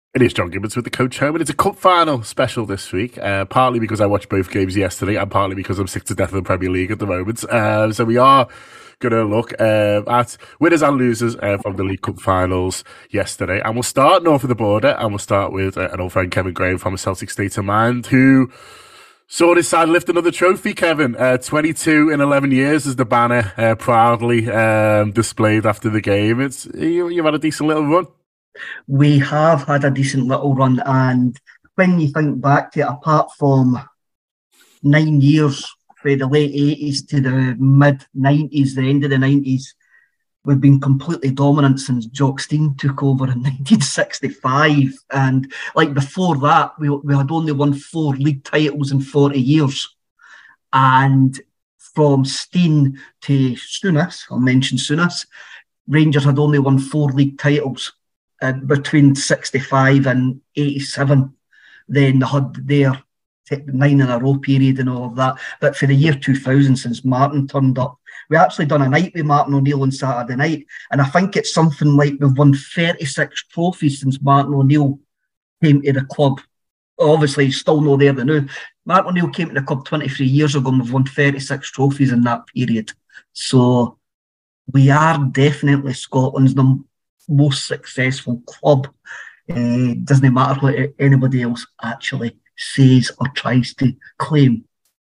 Fan reaction to the weekend’s games, as Erik Ten Hag won his first trophy with Manchester United and Celtic also won the Scottish League Cup.